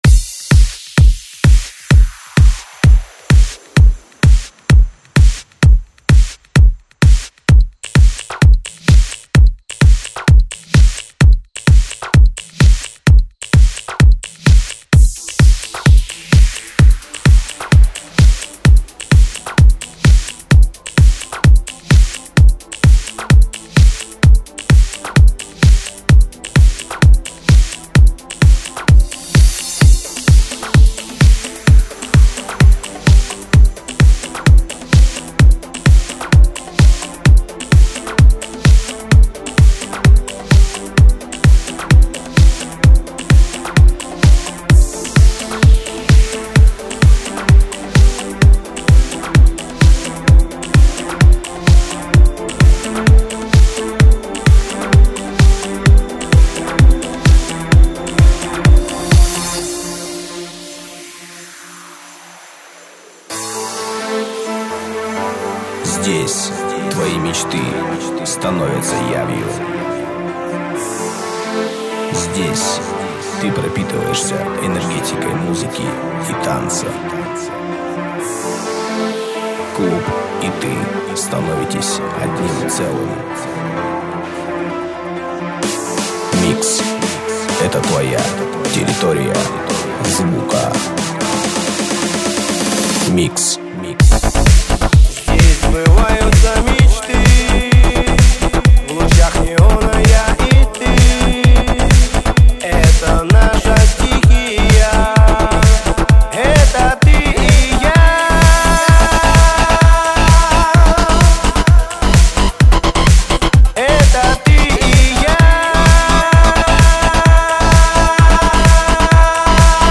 Трэк для ночного клуба